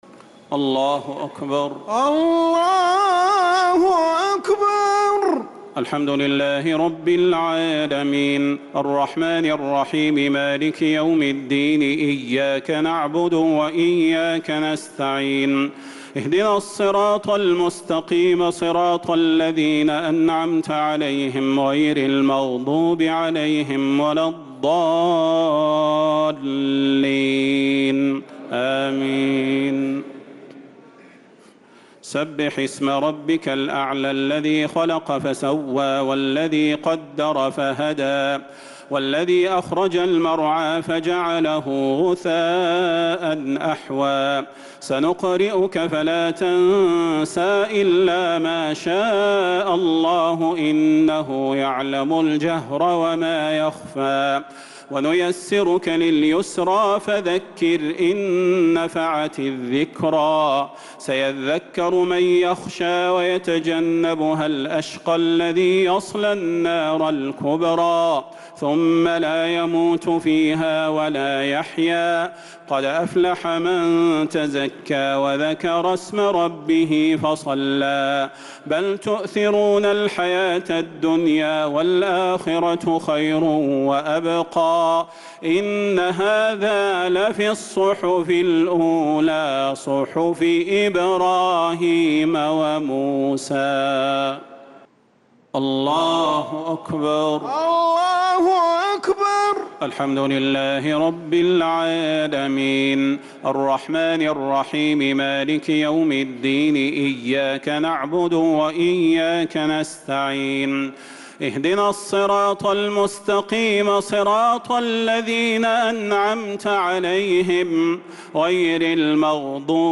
الشفع و الوتر ليلة 17 رمضان 1446هـ | Witr 17th night Ramadan 1446H > تراويح الحرم النبوي عام 1446 🕌 > التراويح - تلاوات الحرمين